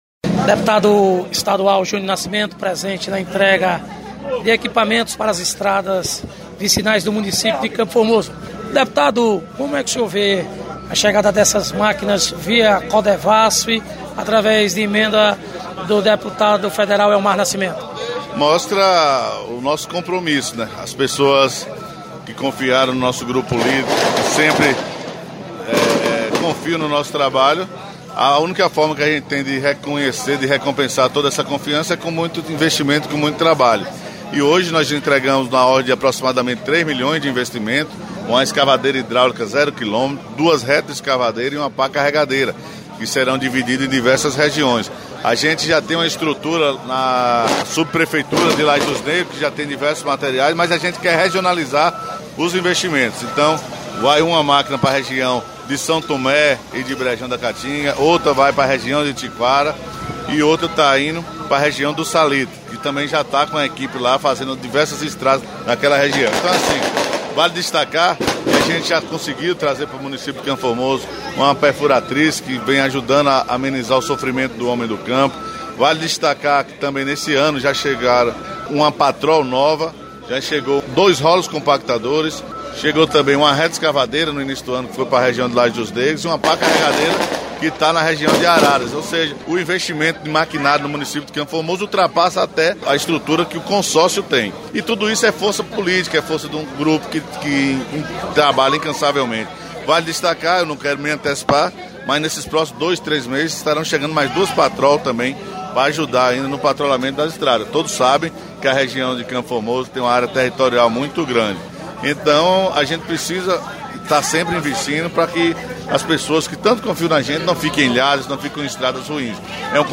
Deputado Estadual Júnior Nascimento fala sobre a chegada das máquinas retroescavadeiras para investimento no município de CFormoso